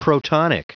Prononciation du mot protonic en anglais (fichier audio)
Prononciation du mot : protonic